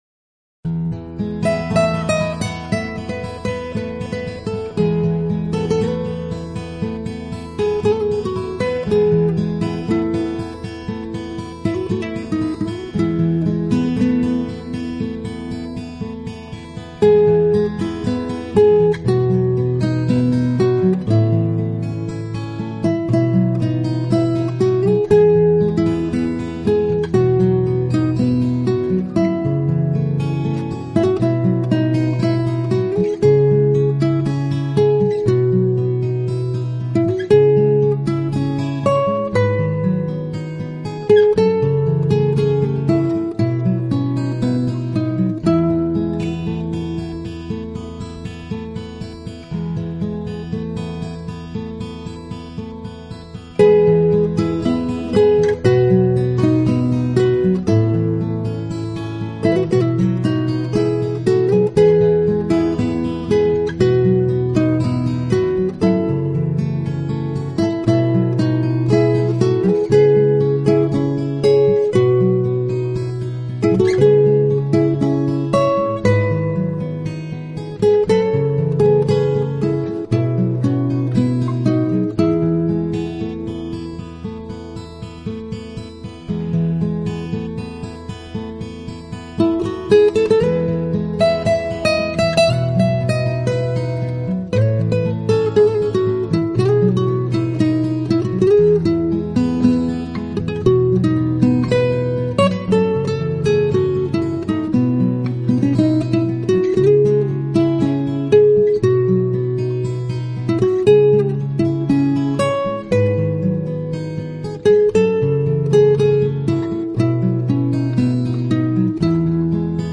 律，悠扬的节奏，给人的是一种美的境界。